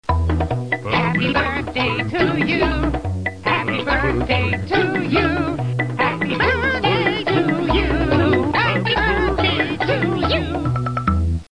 1 channel
00201_Sound_birthdaySong.mp3